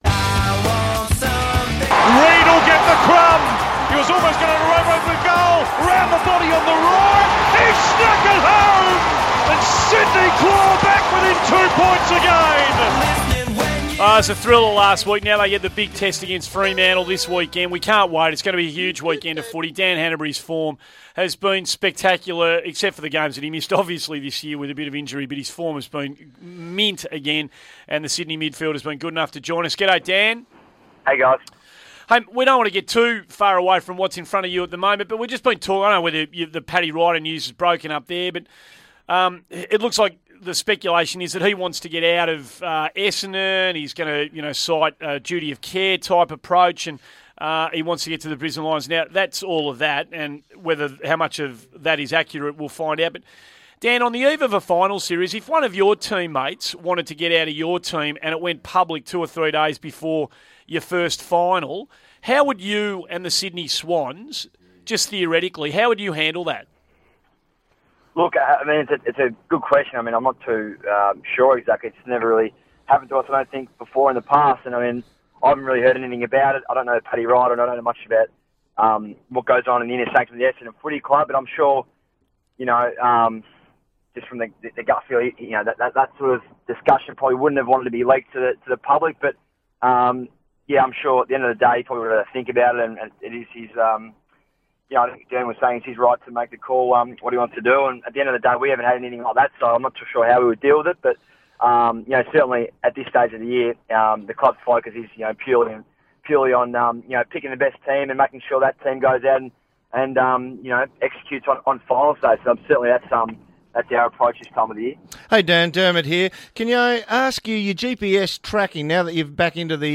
Sydney Swans midfielder Dan Hannebery appeared on 1116SEN's Morning Glory program on Thursday September 4, 2014